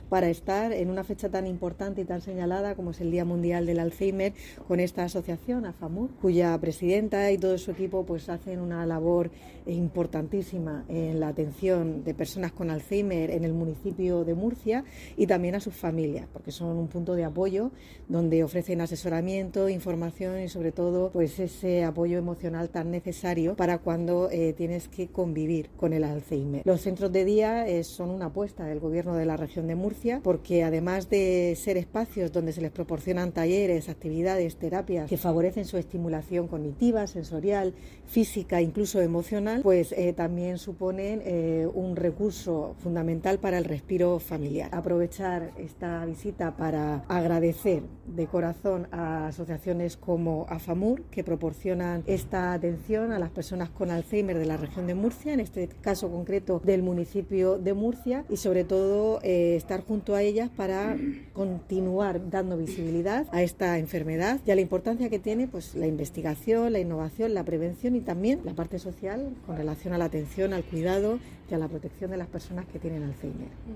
Sonido/ Declaraciones de la consejera de Política Social, Familias e Igualdad, Conchita Ruiz, sobre el apoyo a los enfermos de alzhéimer y sus familias.